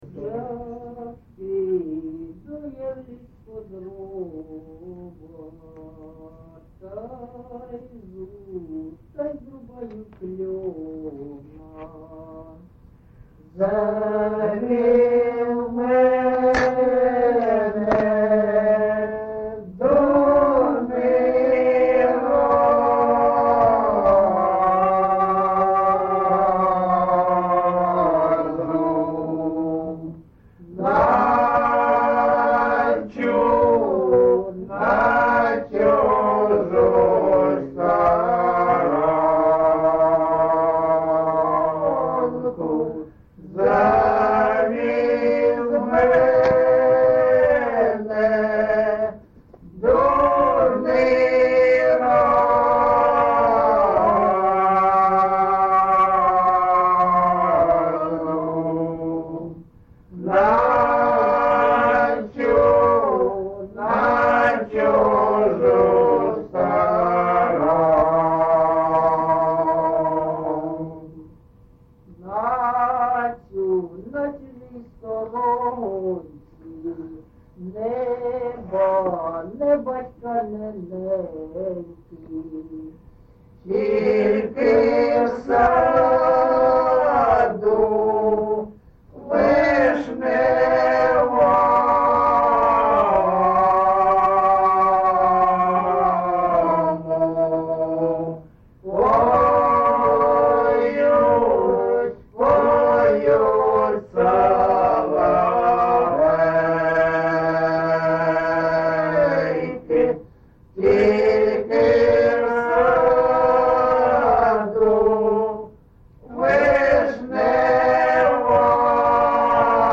ЖанрПісні з особистого та родинного життя
Місце записус. Маринівка, Шахтарський (Горлівський) район, Донецька обл., Україна, Слобожанщина